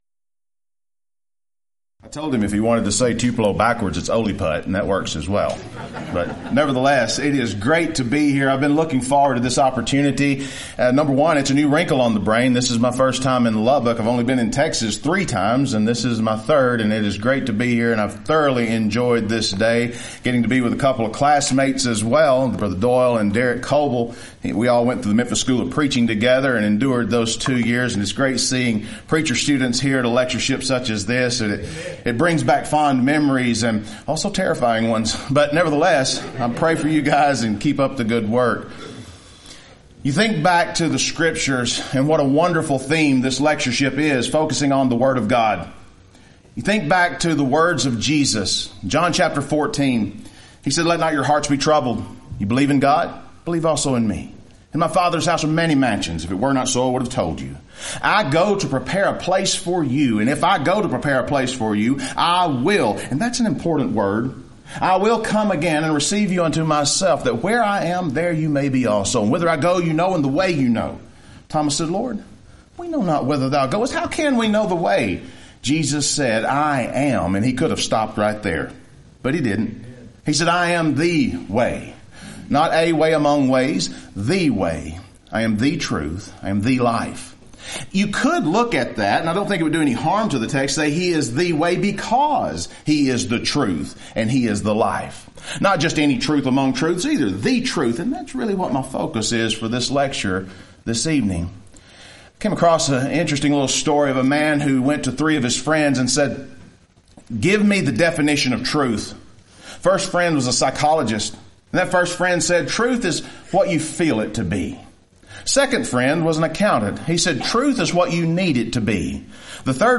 Event: 24th Annual Lubbock Lectures
lecture